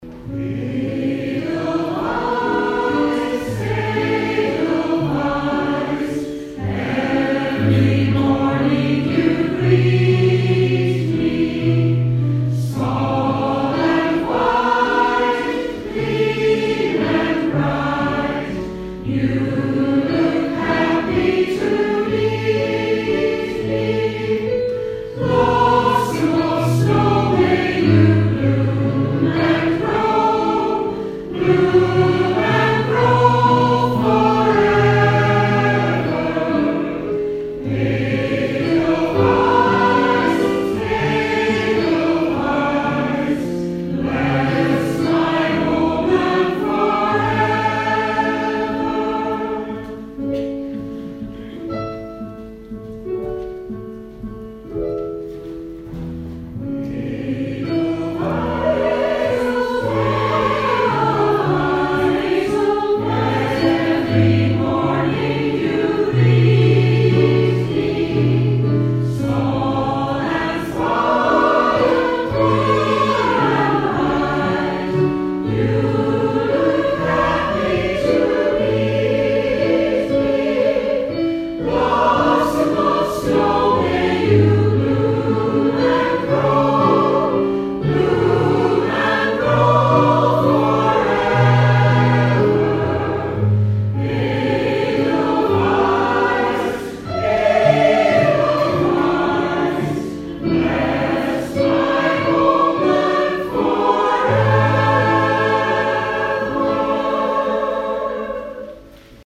A great crowd turned out for the Guild social coffee evening with the Community Choir entertaining us with  a selection of popular songs.
Music from the shows and favourite films, with some scottish extras brought back memories for many of the audience, with a few singing along to those golden favourites.